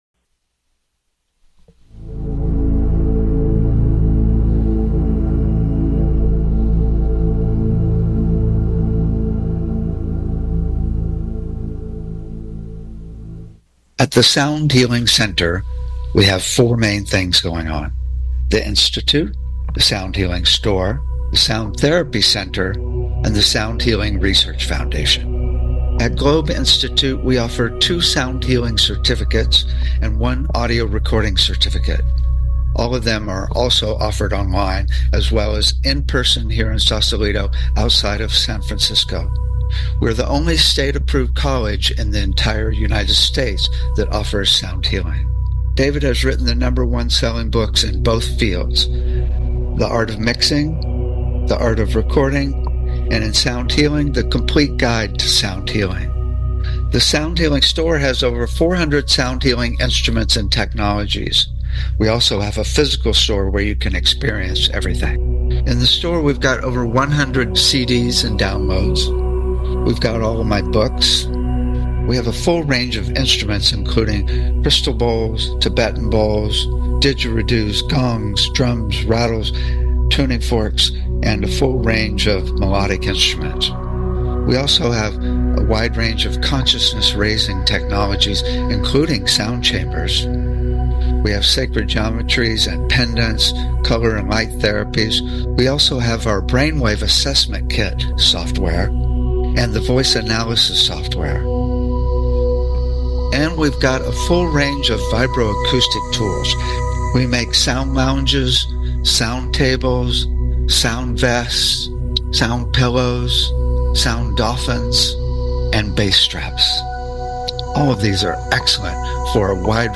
Talk Show Episode, Audio Podcast, Sound Healing and Sound For Anger on , show guests , about Sound For Anger, categorized as Education,Energy Healing,Sound Healing,Love & Relationships,Emotional Health and Freedom,Mental Health,Science,Self Help,Spiritual